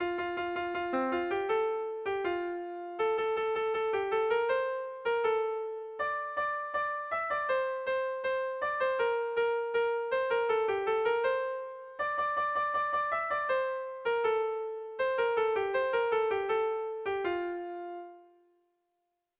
Irrizkoa
ABDEFG